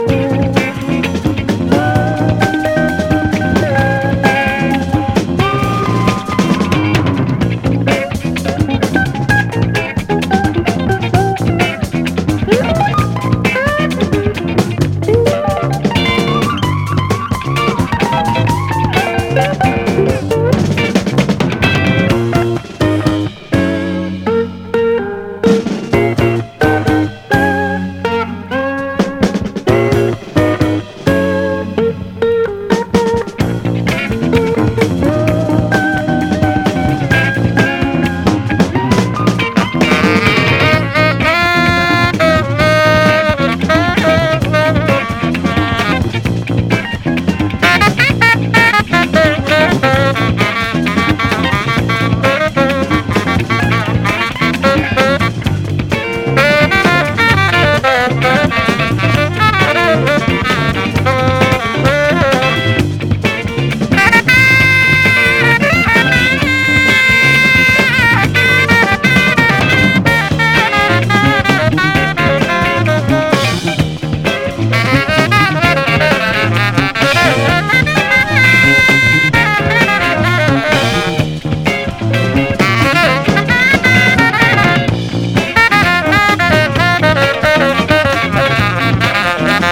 ROCK / 60'S / SURF / HOT ROD / INSTRO
US ORIGINAL盤MONO！